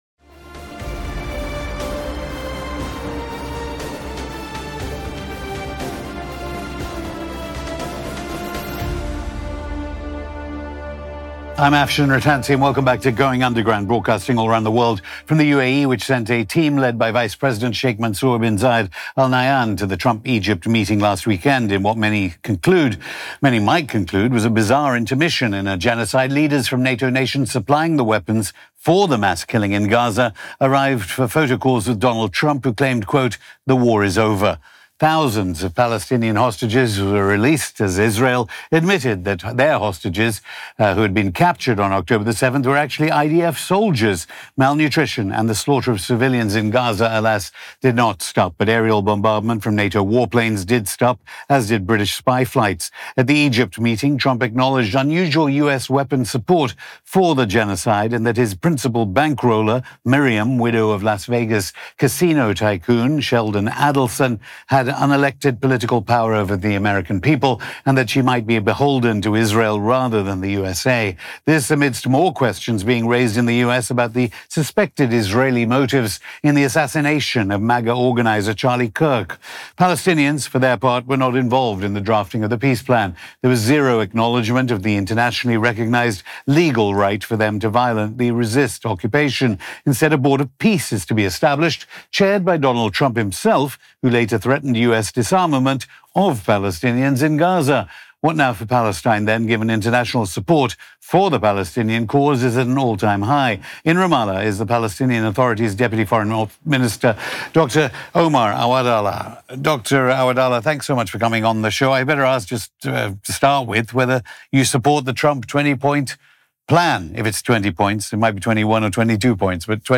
On this episode of Going Underground, we speak to Dr. Omar Awadallah, Deputy Minister of Foreign Affairs of the Palestinian Authority.